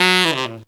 Index of /90_sSampleCDs/Zero-G - Phantom Horns/TENOR FX 2